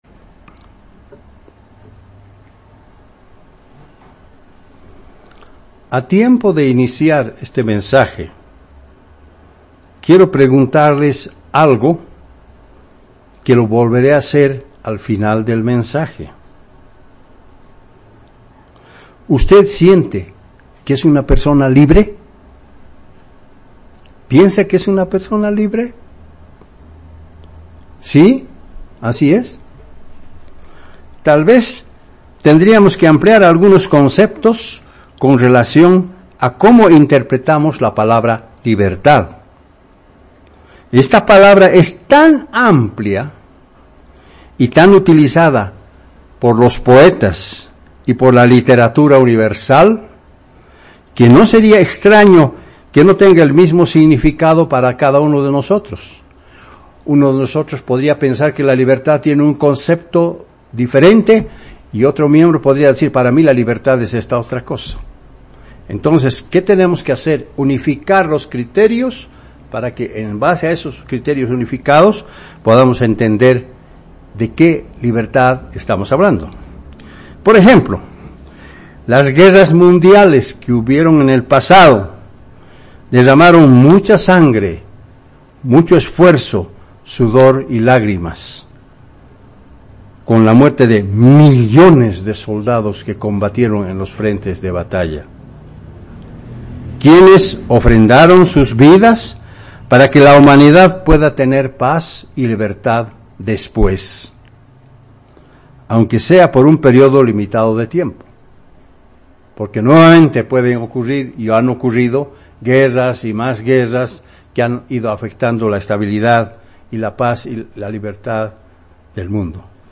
¿Estamos conscientes del precio real de la misma?. Mensaje entregado el 4 de noviembre de 2017